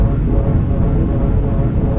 drone6.wav